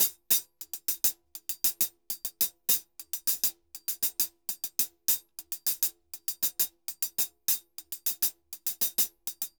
HH_Salsa 100_2.wav